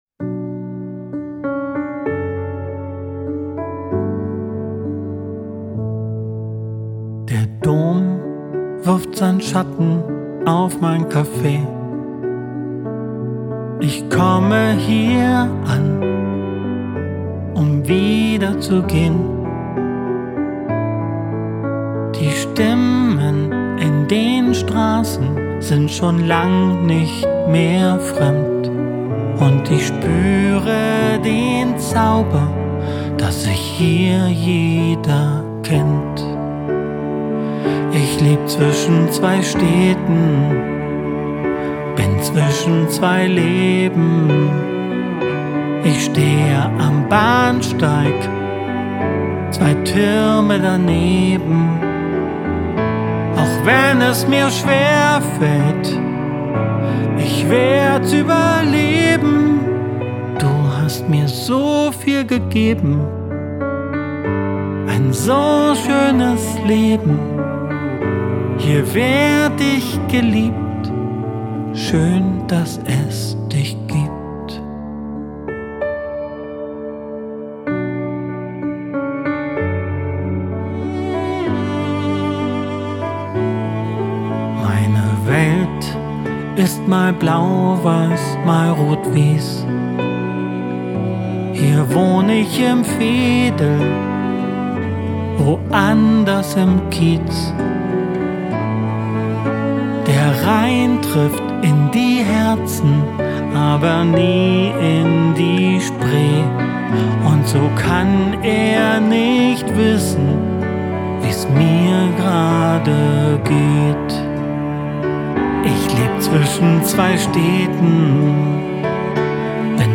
Gitarre & Gesang:
Klavier:
Akkordeon:
Bass:
Cello:
Chor: